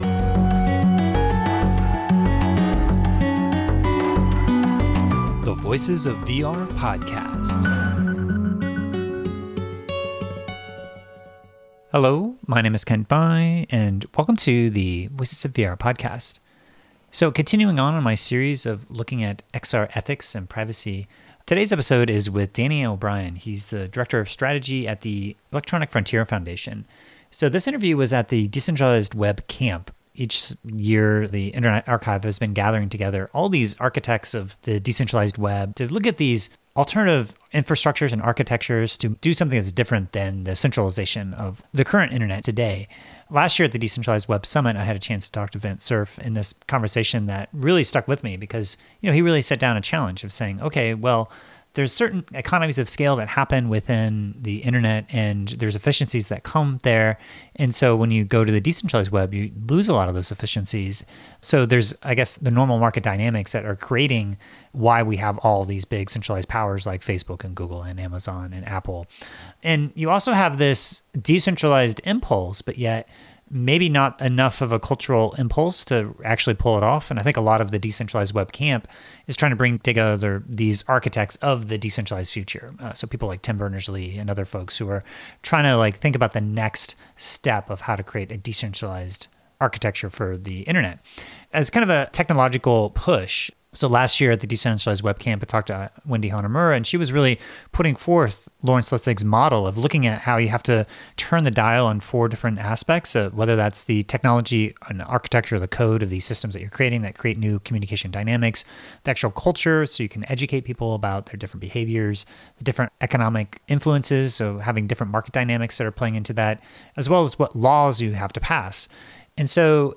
So this interview was at the Decentralized Web Camp.